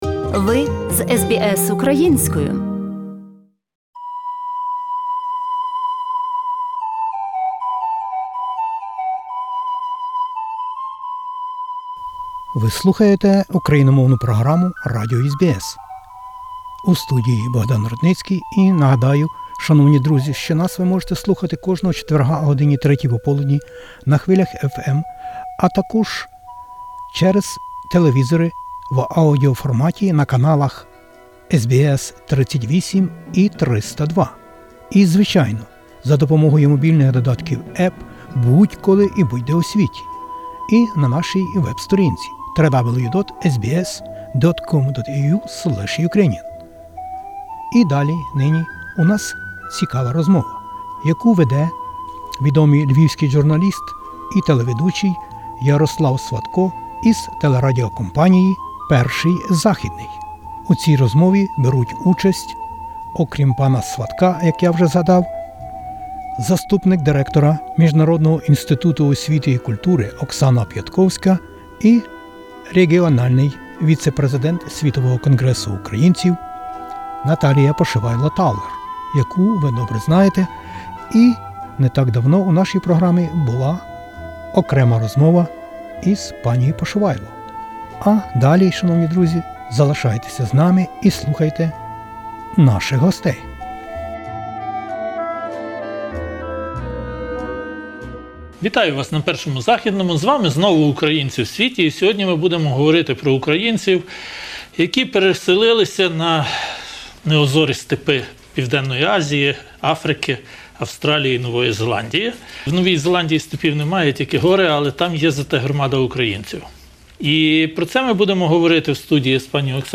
Нині - дещо про українців та їх нащадків в Австралії, Новій Зеландіі, Синґапурі, ОАЕ, Гонконґу, Малайзії, Йорданії, Індії, Лівані, Єгипеті та Південній Африці. А розмову про це веде відомий журналіст і телеведучий